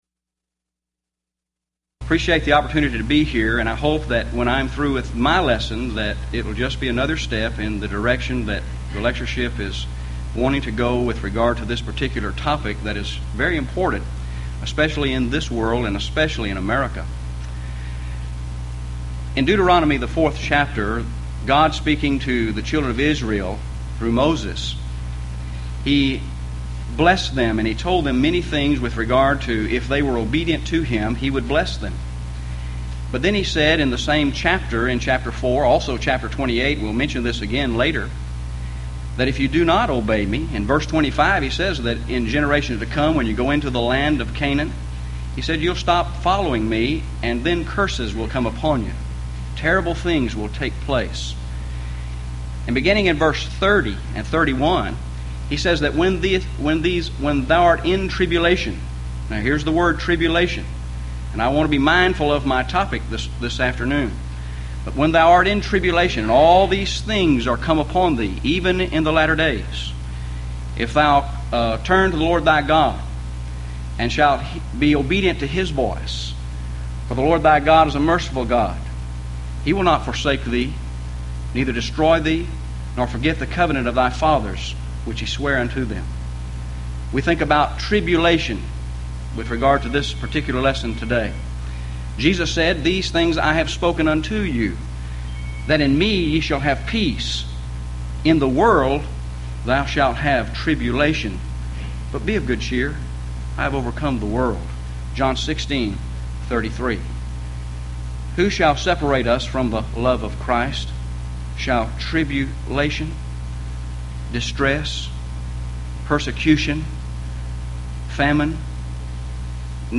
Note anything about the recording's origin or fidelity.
Event: 1997 HCB Lectures Theme/Title: Premillennialism